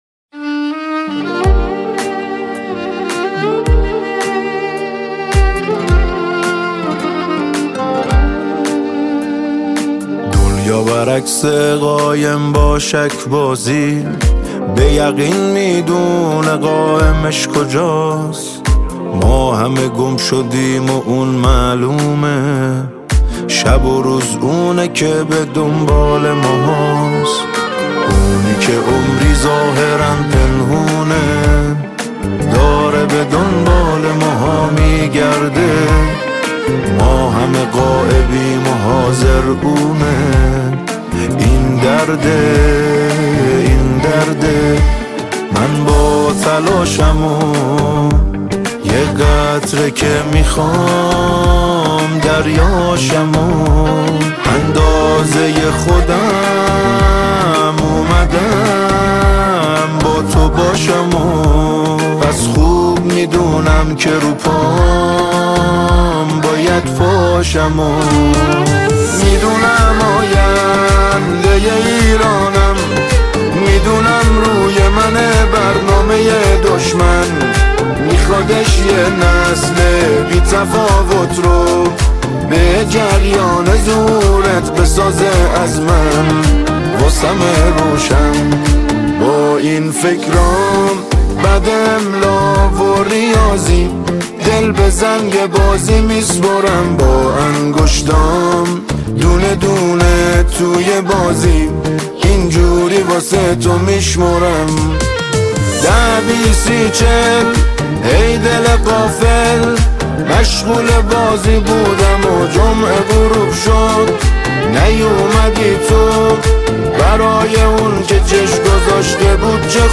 ژانر: سرود ، سرود انقلابی ، سرود مذهبی ، سرود مناسبتی